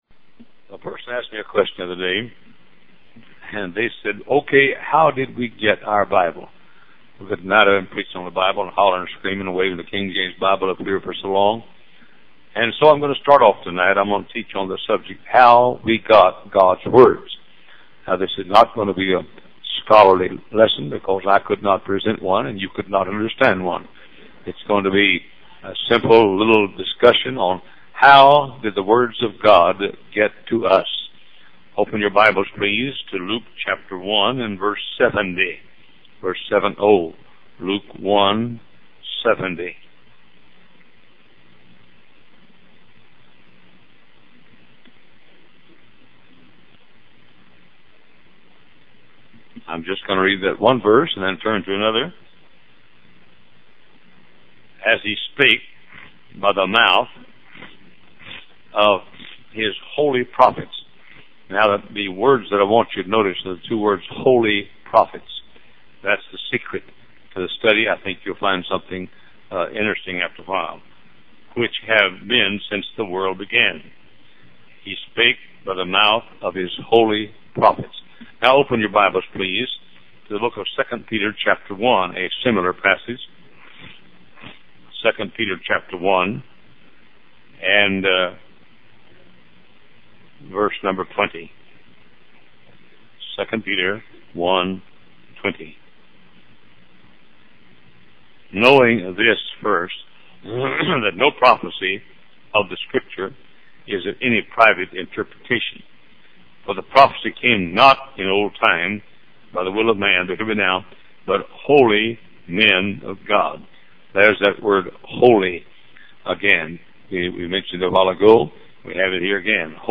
Wise Desire Ministries helps convey various Christian videos and audio sermons.